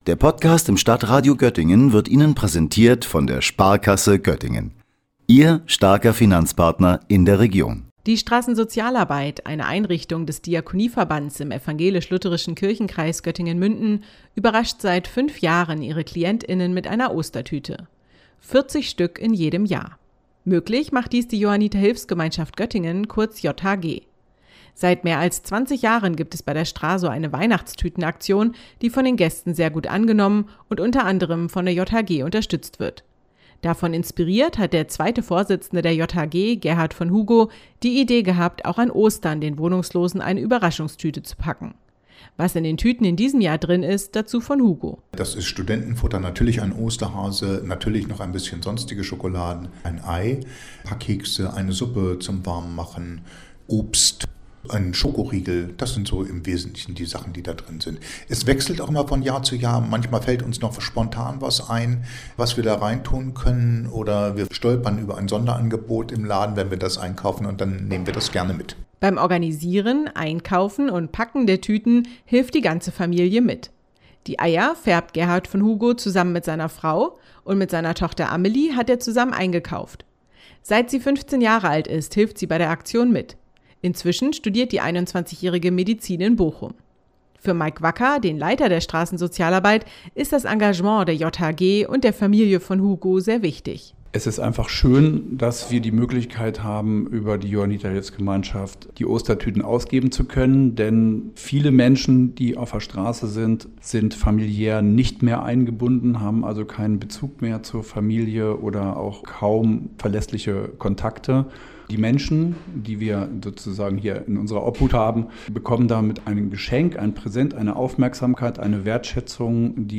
Sendung: Heaven@11 Bürgerfunk